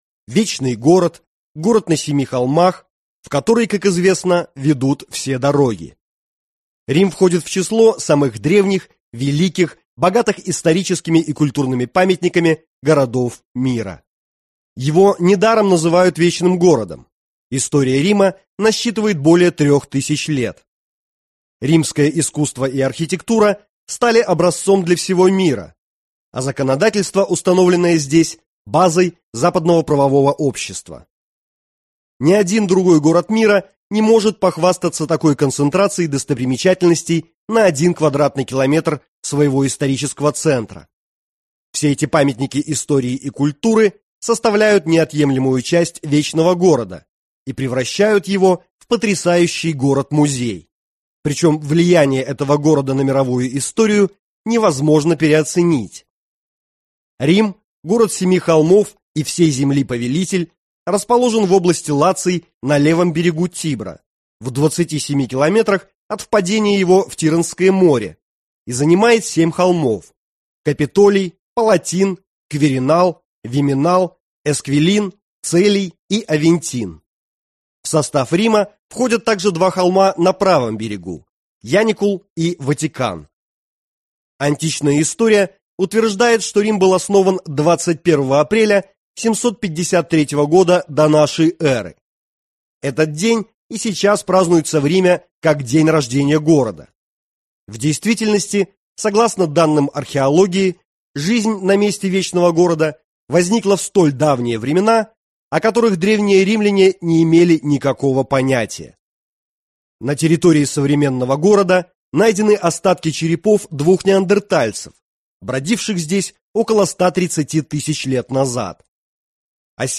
Аудиокнига Путеводитель по Риму | Библиотека аудиокниг